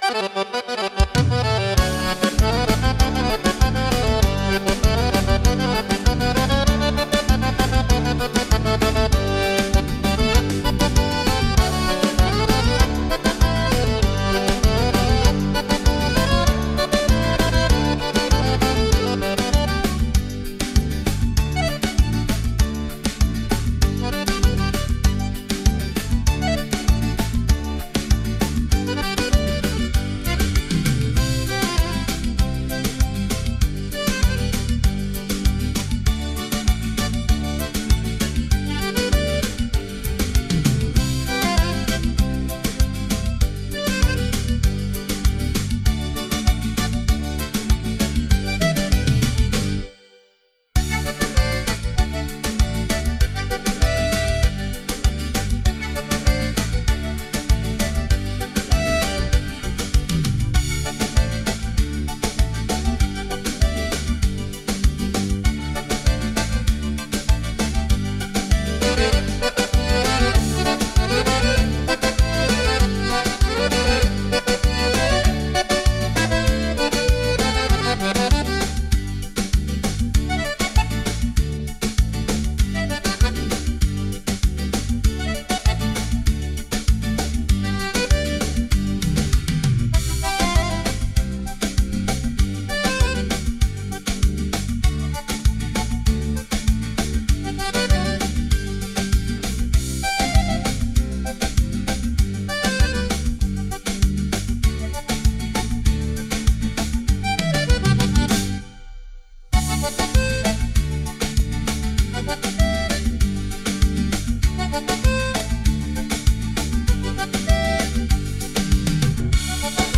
02-PLAYBACK_A Branca Flor do Cafezal - Bugio + Acordeon.wav